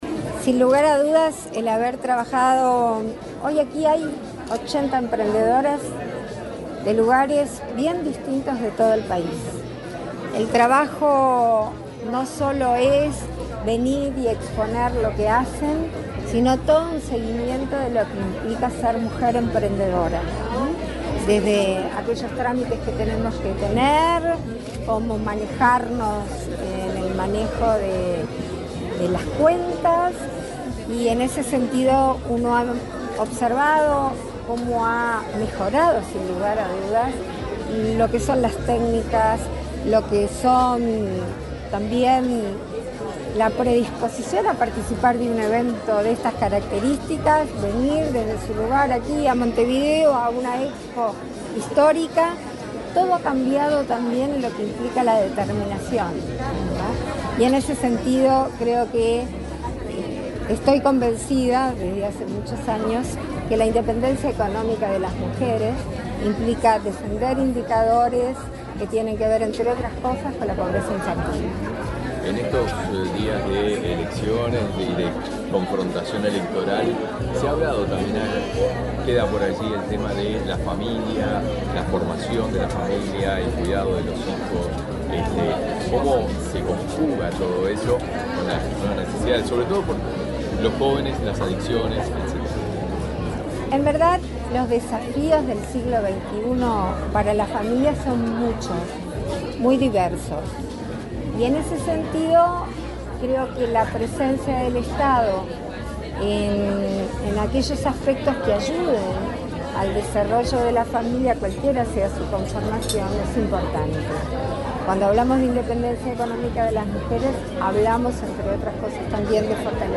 Declaraciones de la presidenta en ejercicio, Beatriz Argimón, a la prensa
La presidenta de la República en ejercicio, Beatriz Argimón, dialogó con la prensa, luego de participar en la tercera edición del evento Diálogos y